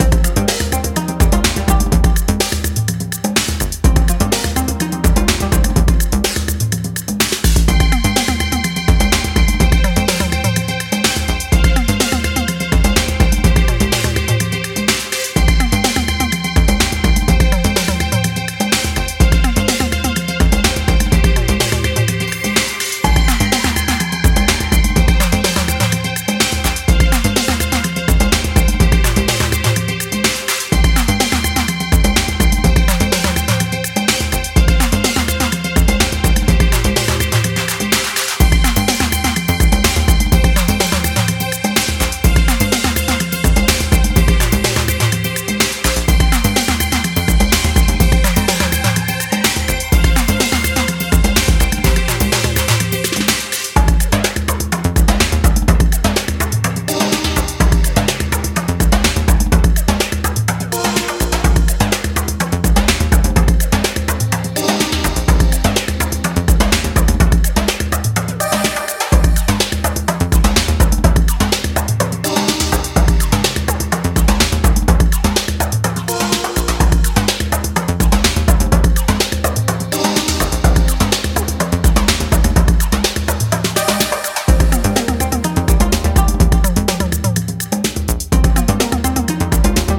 ジャンル(スタイル) HOUSE / TECHNO